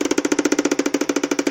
Terkotanie
terkotanie.mp3